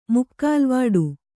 ♪ mukkālvāḍu